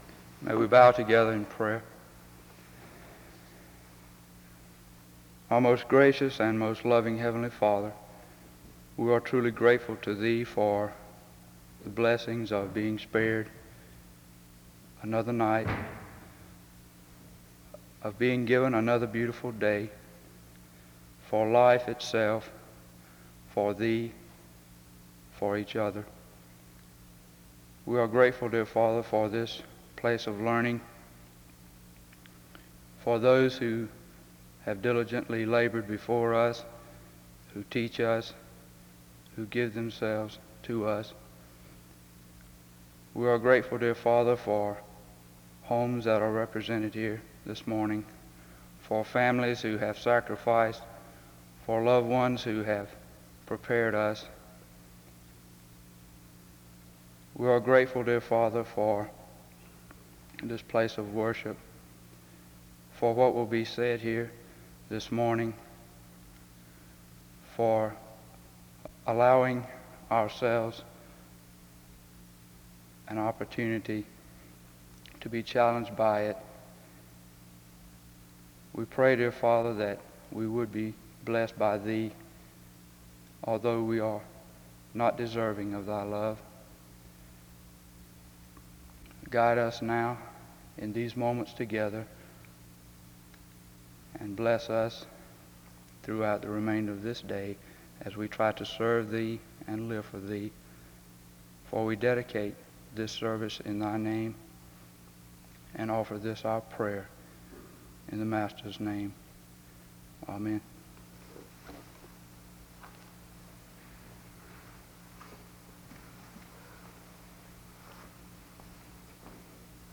The service begins with a word of prayer from 0:00-1:50.
SEBTS Chapel and Special Event Recordings SEBTS Chapel and Special Event Recordings